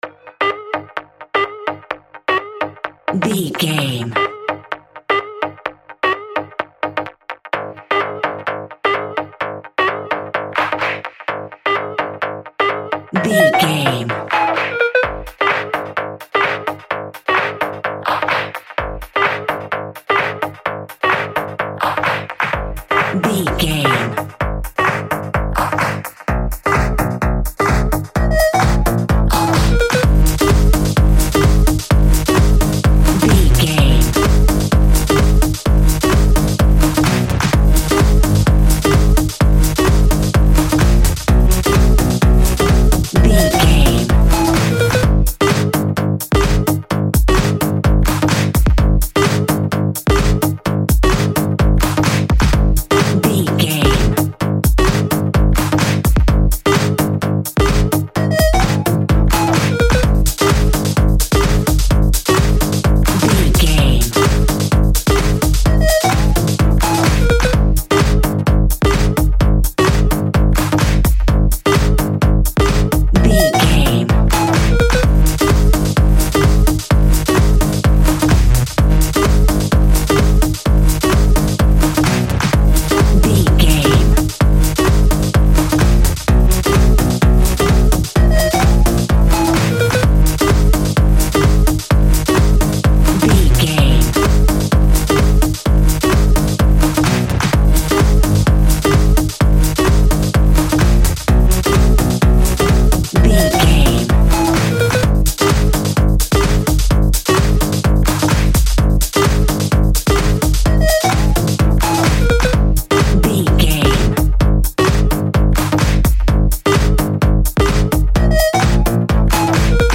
Aeolian/Minor
G#
groovy
driving
energetic
uplifting
hypnotic
drum machine
synthesiser
funky house
deep house
nu disco
upbeat
funky guitar
wah clavinet
synth bass
horns